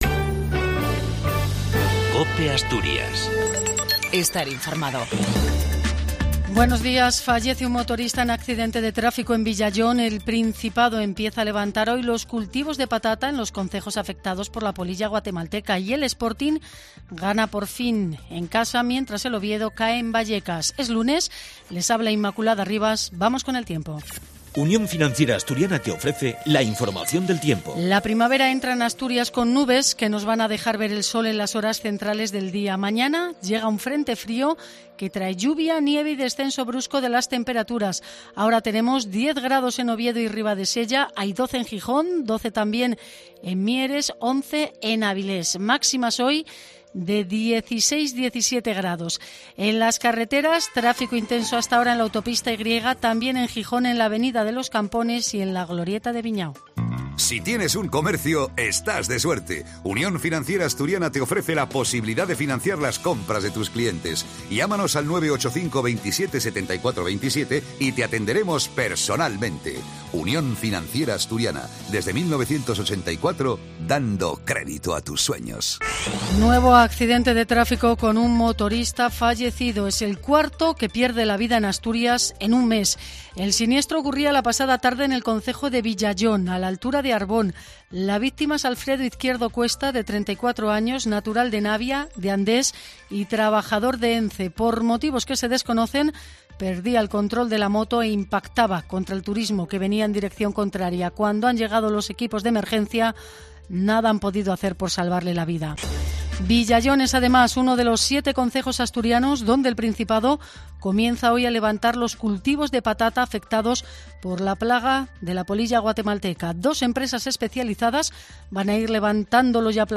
AUDIO: NOTICIAS DE ASTURIAS